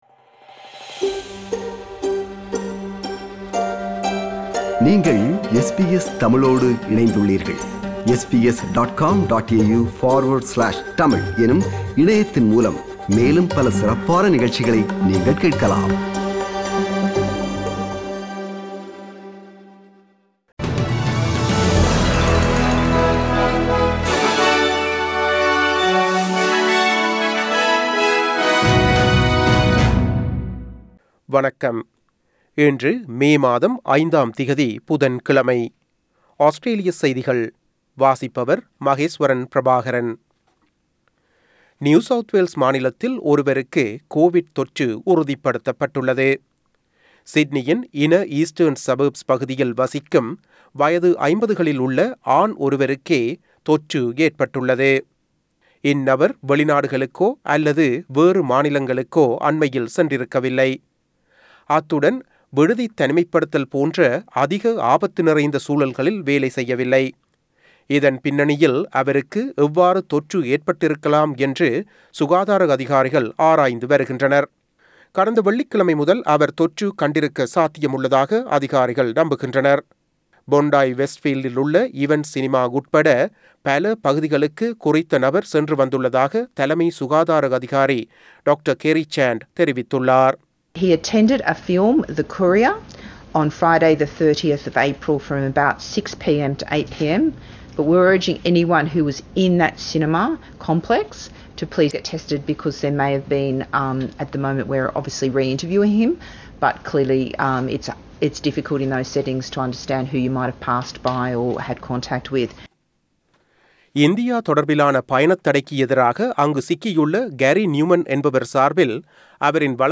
Australian news bulletin for Wednesday 05 May 2021.